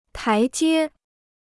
台阶 (tái jiē): steps; flight of steps.